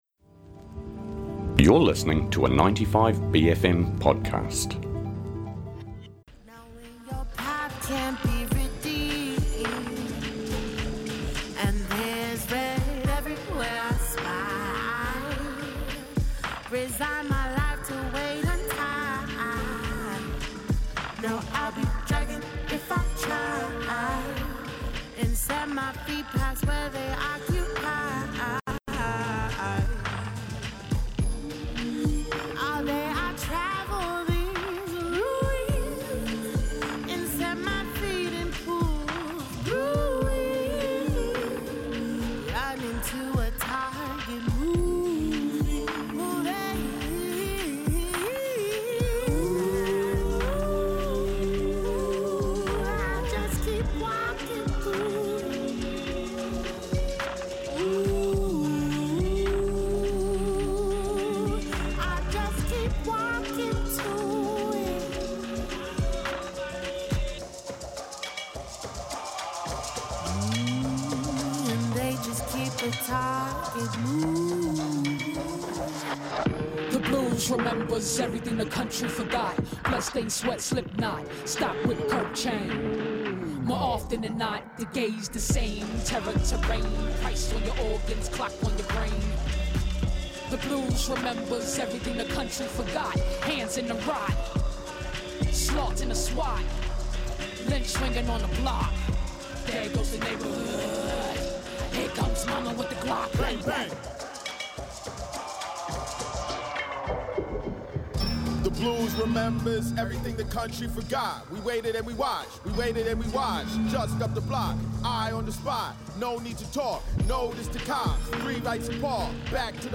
A simple feed of all the interviews from our many and varied special bFM Breakfast guests.